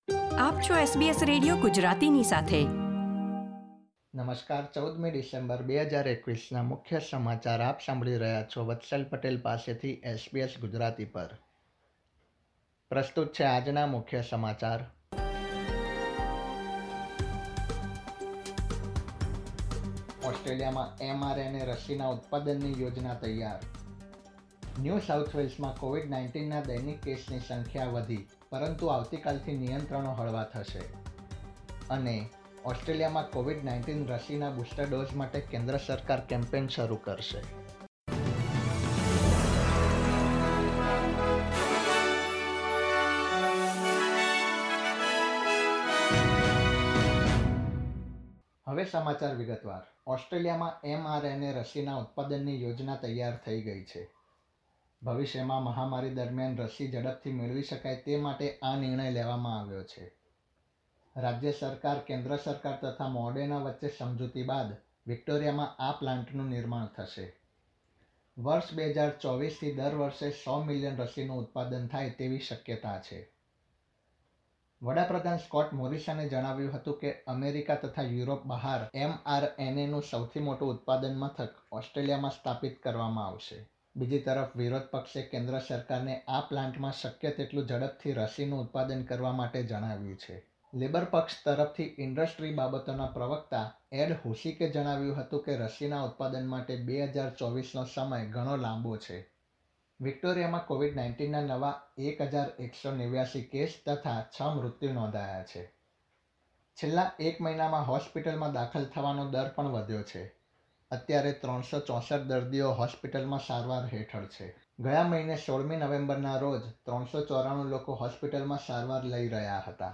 SBS Gujarati News Bulletin 14 December 2021